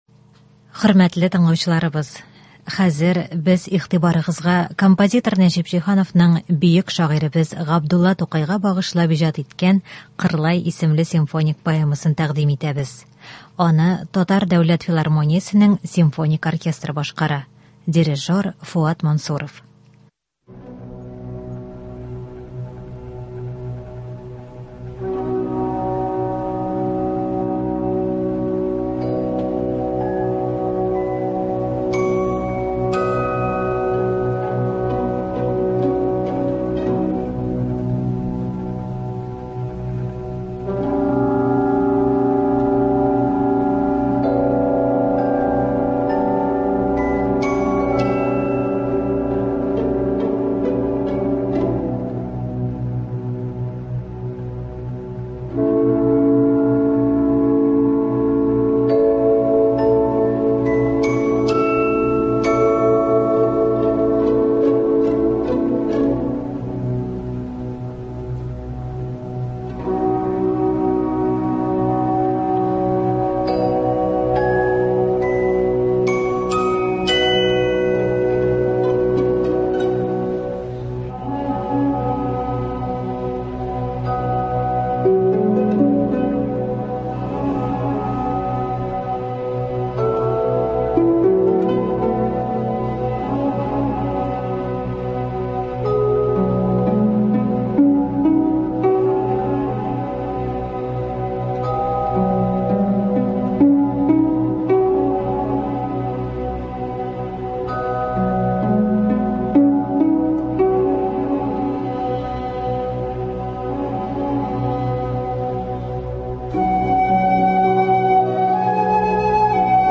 “Кырлай”. Симфоник поэма.